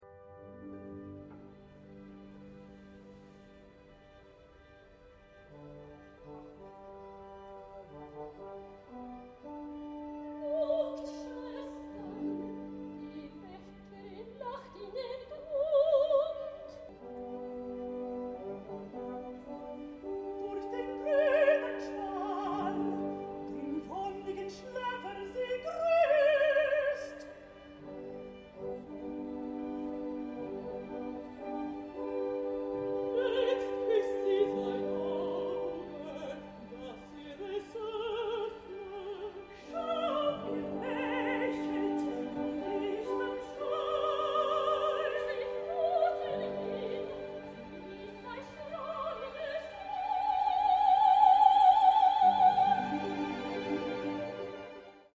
De klingande exemplen är i mp3-format och hämtade från den radiosända premiären den 10 september 2005.
Rhenguldsmotivet är hornets svar när solen bryter vattenytan.
Hornist